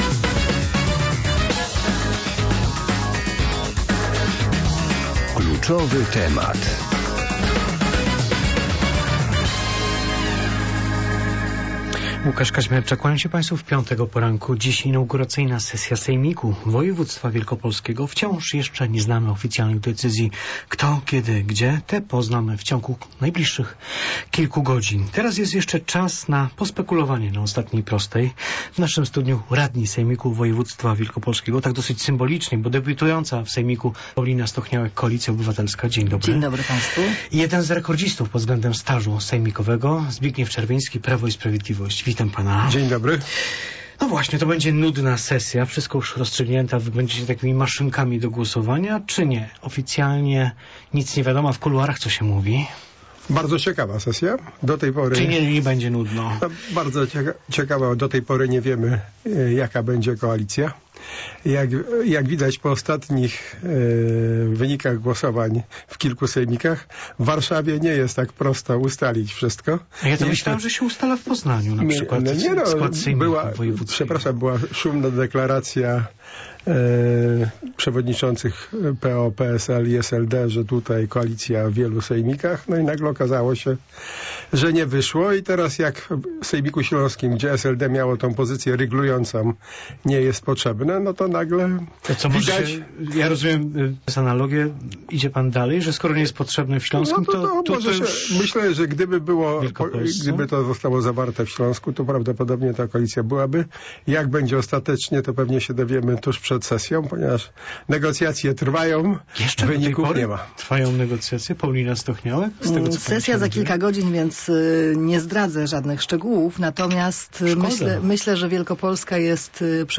Gośćmi Kluczowego Tematu byli radni sejmiku województwa wielkopolskiego: Paulina Stochniałek (Koalicja Obywatelska) i Zbigniew Czerwiński (Prawo i Sprawiedliwość).